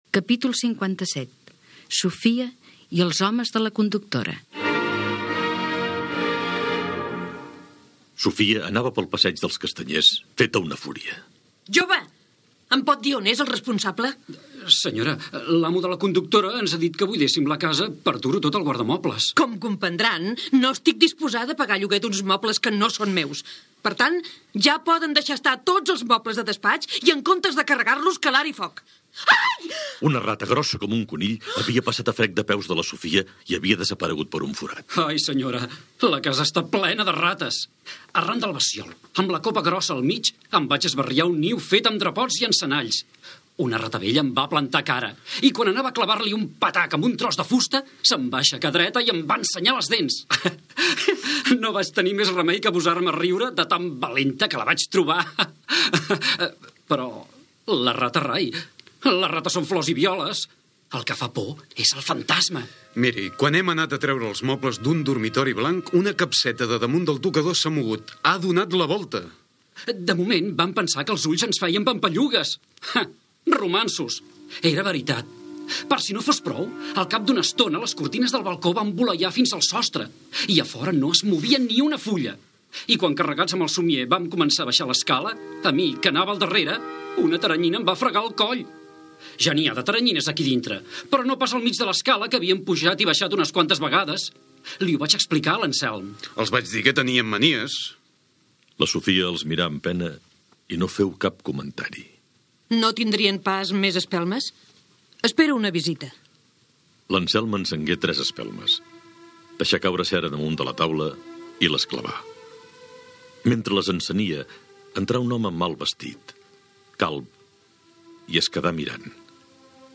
Radionovel·la
Ficció